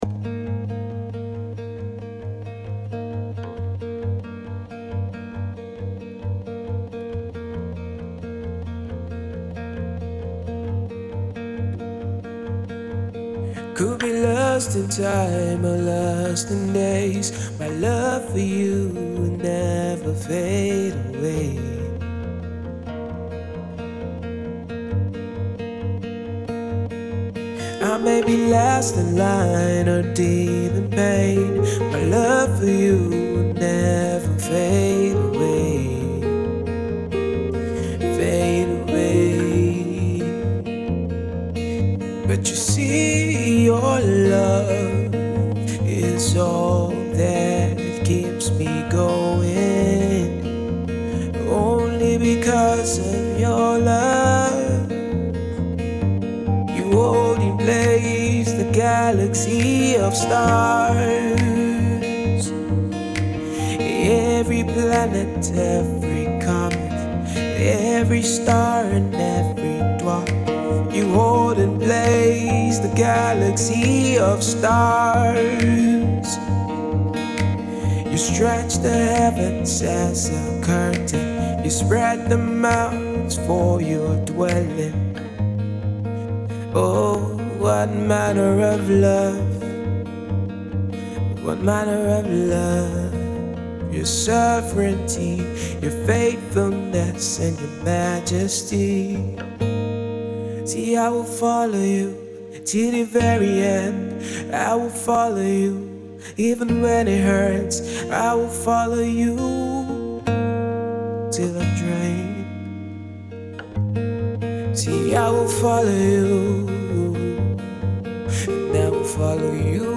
gospel
acoustic song